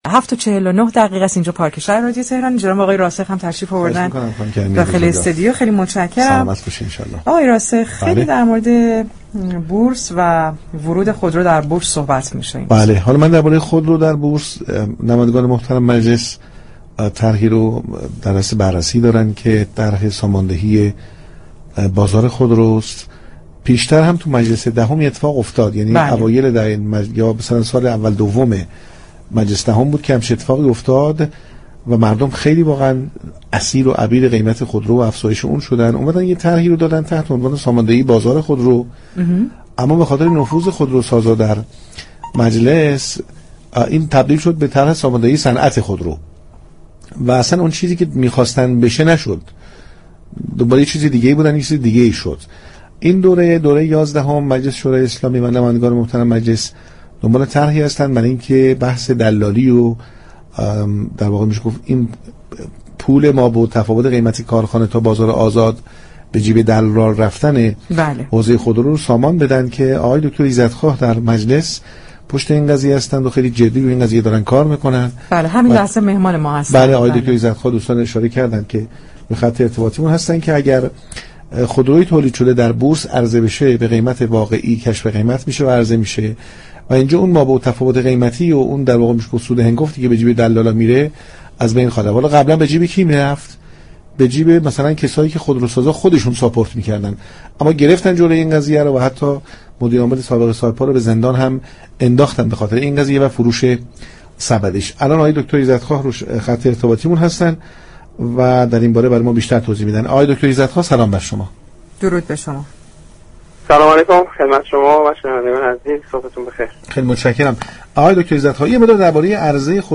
روح الله ایزدخواه عضو كمیسیون صنایع و معادن مجلس در گفتگوی تلفنی با پارك شهر گفت: بورس كالا در حال حاضر شفاف ترین محل توزیع است. در صورت ورود خودرو به بورس ، این كالا با قیمت مناسب و بی واسطه به دست مصرف كننده می رسد.